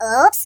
Oops.ogg